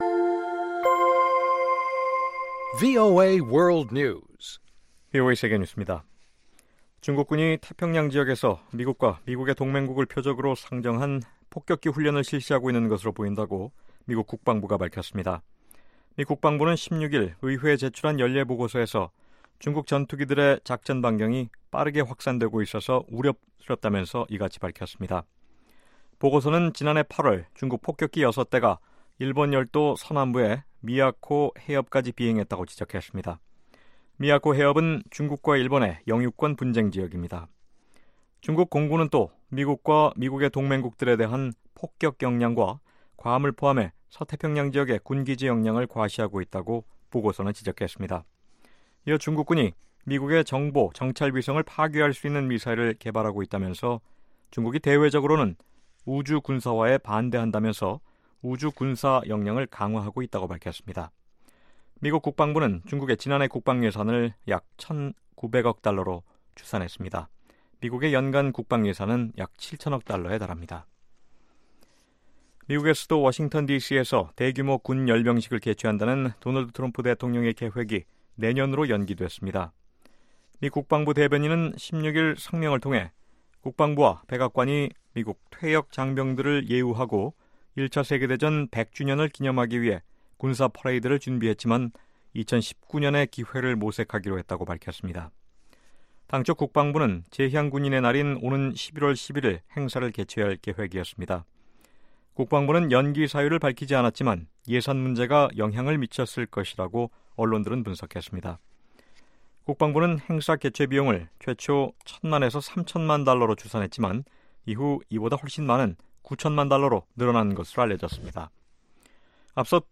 VOA 한국어 아침 뉴스 프로그램 '워싱턴 뉴스 광장' 2018년 8월 18일 방송입니다. 도널드 트럼프 대통령은 미국의 대중 무역 정책으로 인해 중국이 북한 문제에 악영향을 주고 있을 수 있다고 밝혔습니다. 미국 국무부는 미국의 제재에 대한 보복 조치를 예고한 러시아에 변명 대신 대북 제재를 철저히 이행하라고 촉구했습니다.